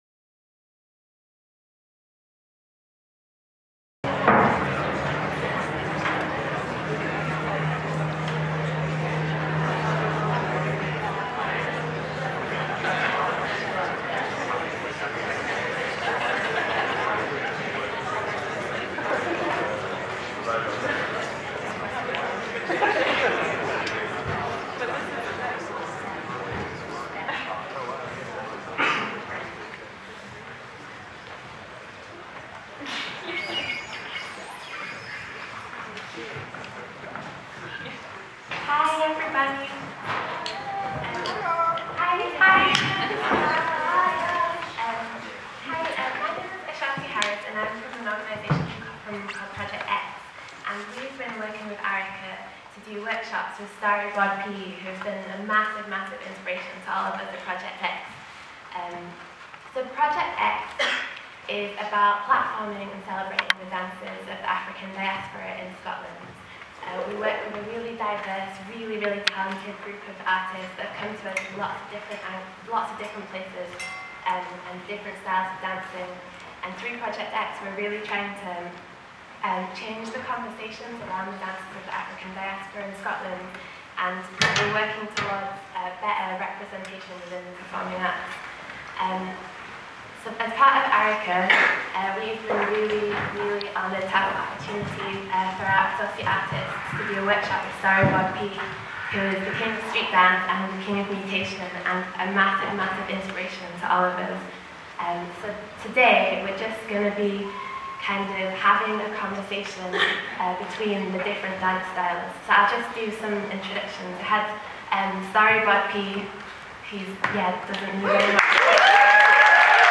A performative conversation between dancers and audience, Speech Captions Body Language was collectively devised by Storyboard, Project X and Arika, and developed out of a workshop hosted by Project X